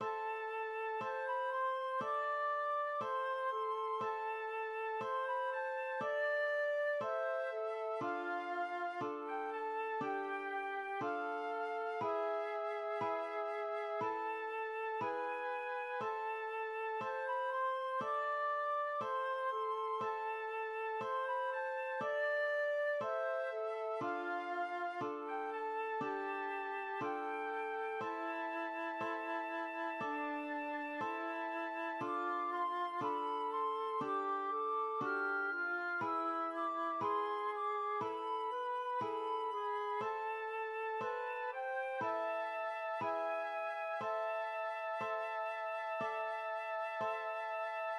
\time 4/4
\key a \minor
\tempo 4=120
\set Staff.midiInstrument="flute"